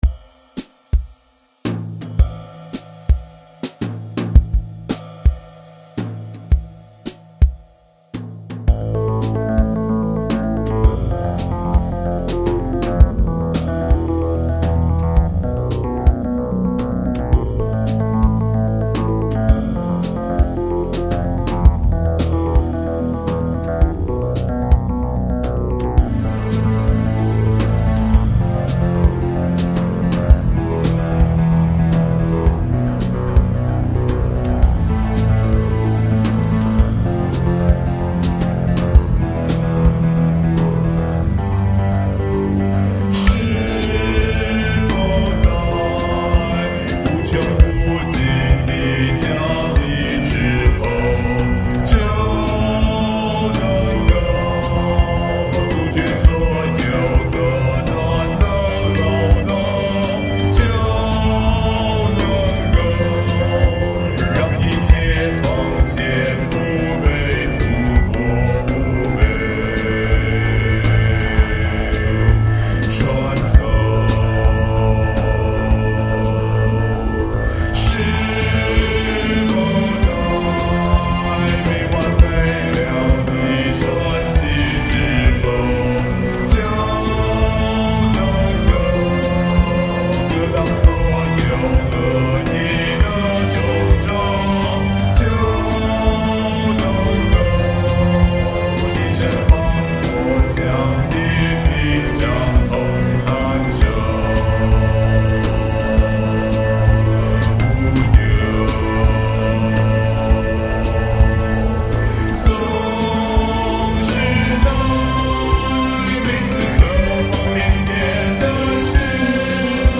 弄了一個自己都唱不上去的東西。
曲的問題也差不多：調子太沉，缺乏對比和起伏。